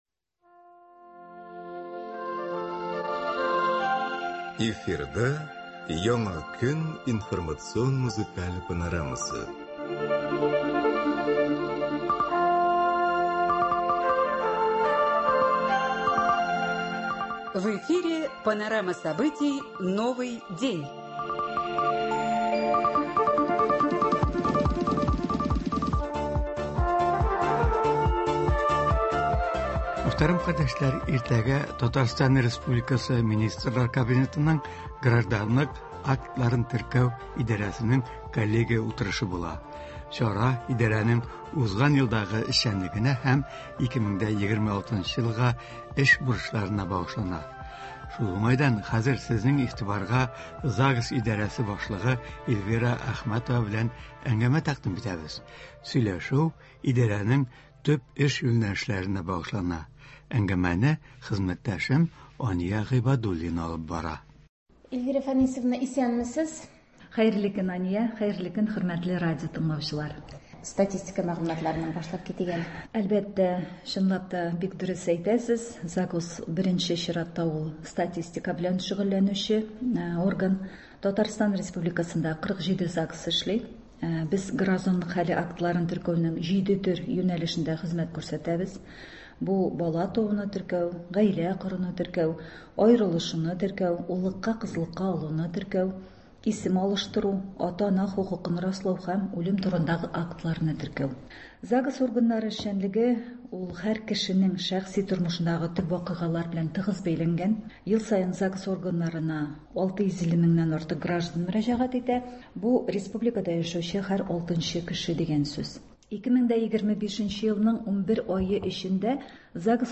Туры эфир (21.01.26)